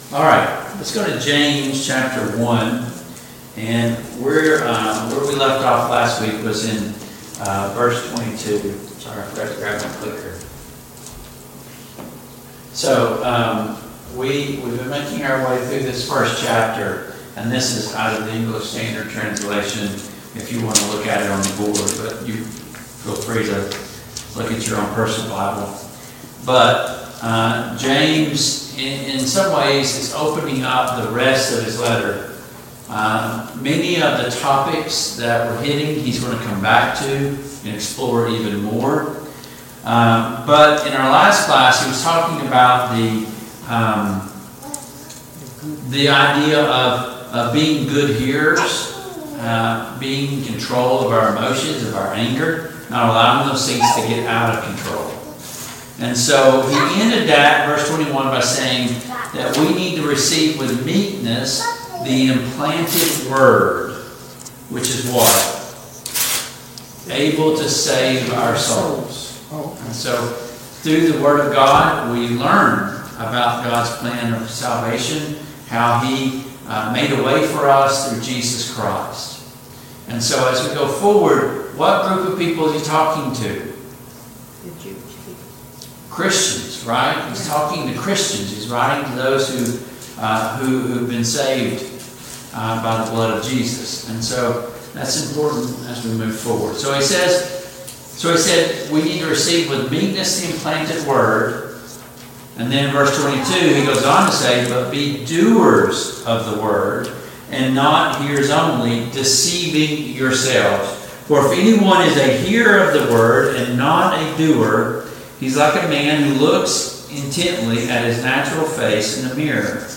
Study of James and 1 Peter and 2 Peter Passage: James 1:22-25 Service Type: Family Bible Hour « Do you love Jesus?